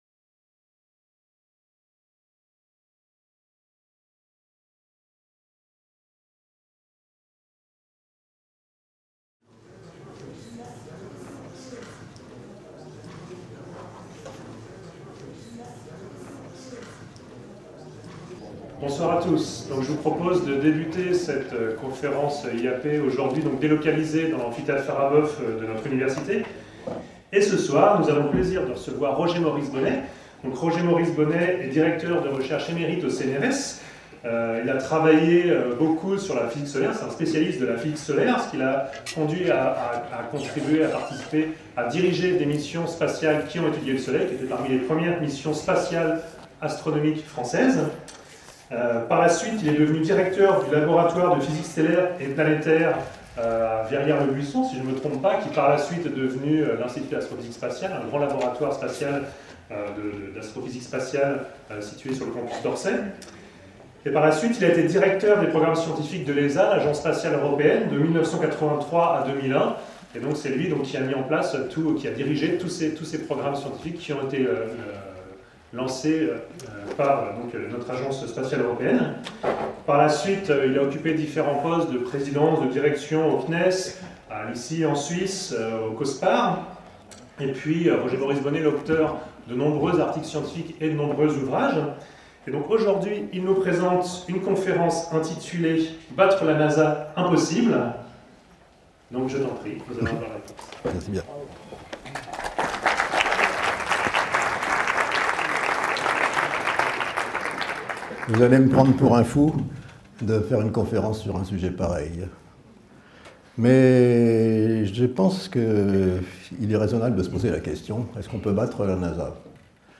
La conférence offre en exemple l'approche suivie par l'Europe, et avec elle l'Agence spatiale européenne (ESA) et ses états membres, qui l'ont conduite à devenir la seconde puissance spatiale scientifique mondiale.